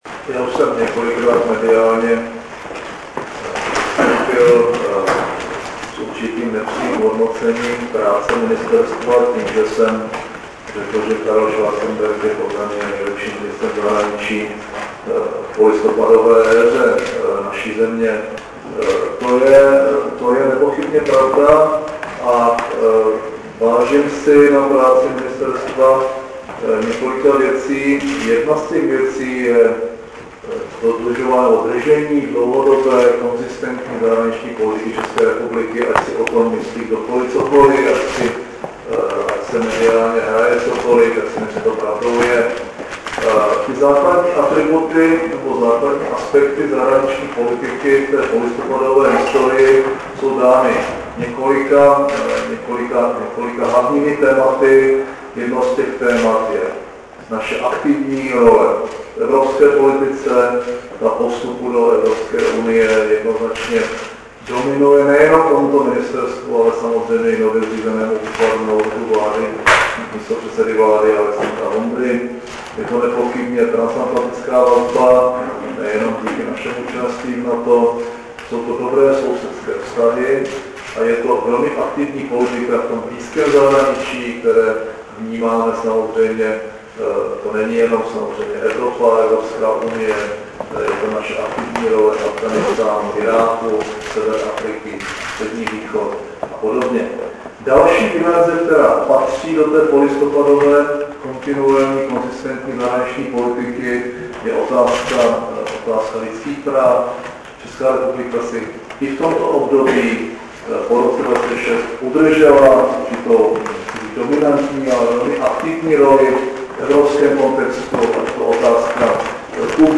Tisková konference předsedy vlády České republiky Mirka Topolánka a ministra zahraničních věcí Karla Schwarzenberga, 21. července 2008